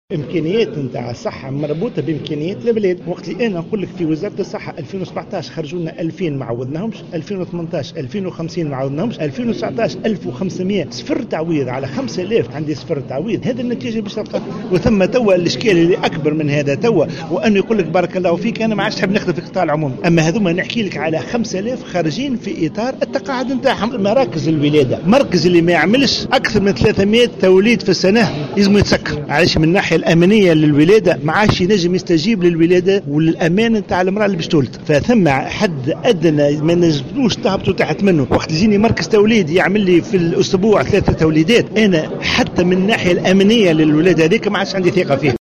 وأكد في تصريح لمراسل "الجوهرة أف أم" على هامش مؤتمر طبي للنقابة الوطنية لأطباء القطاع الخاص فرع المنستير، أنه تم تسجيل "صفر تعويض" مقابل الأطباء الذين حصلوا على التقاعد دون اعتبار الأطباء الذين يرفضون العمل بالقطاع العمومي، منبها إلى صعوبات الصحة العمومية في ظل ضعف الإمكانات.